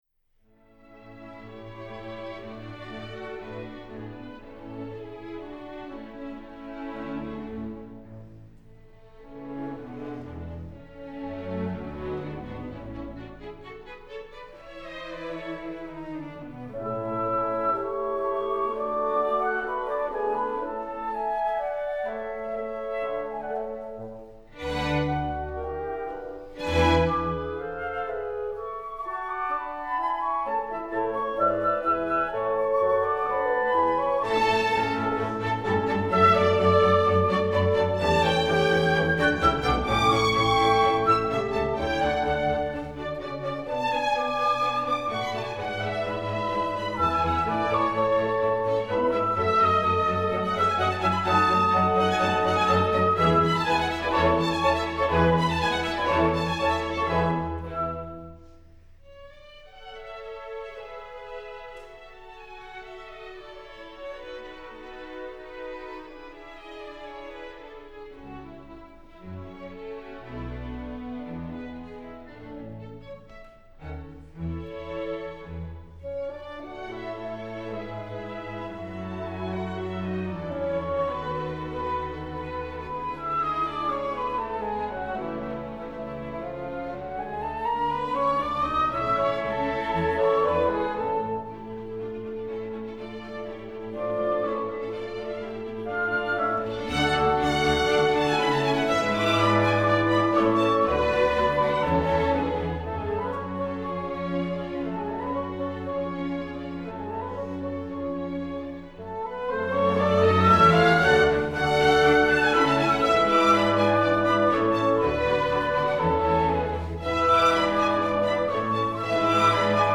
Listen to historic chamber music recordings online as heard at Vermont's Marlboro Music Festival, classical music's most coveted retreat since 1951.
Concerto in A Major for Piano and Orchestra, K. 488